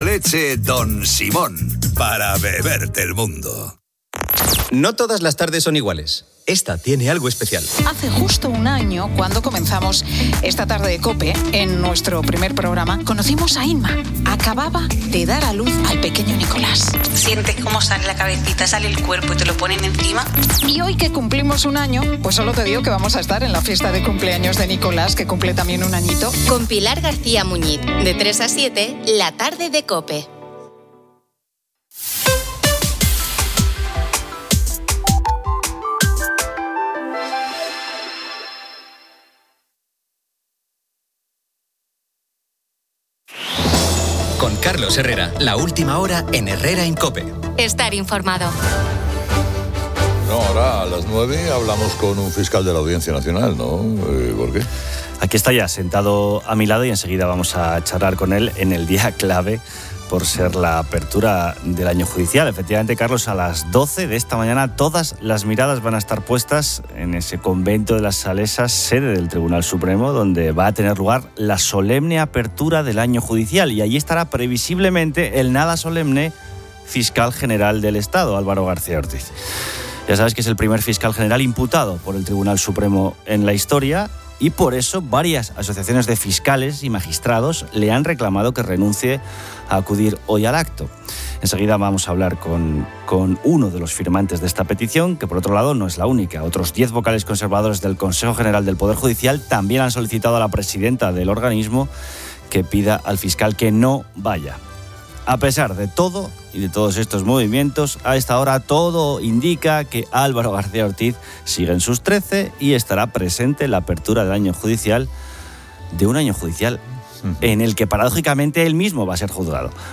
El audio presenta fragmentos de la programación de la Cadena COPE, incluyendo anuncios de Leche Don Simón y Seguros NARA, entre otros.